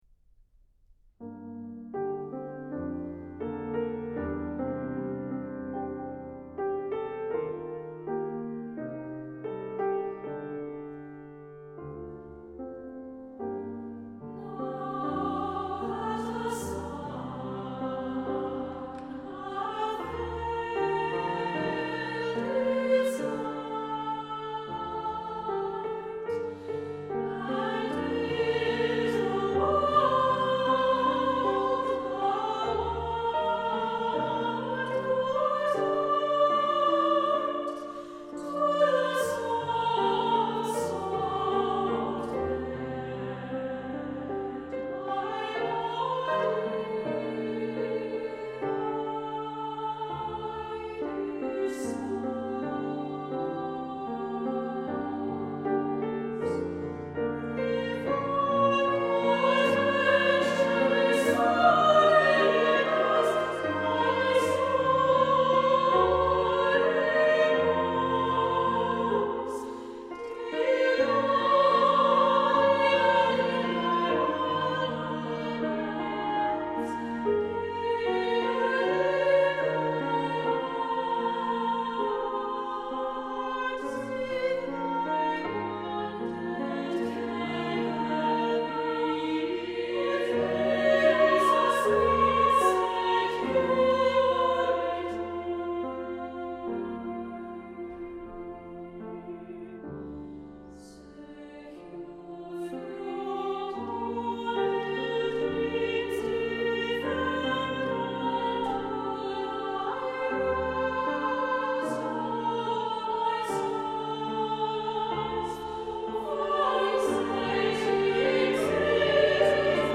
Voicing: SSAA and Piano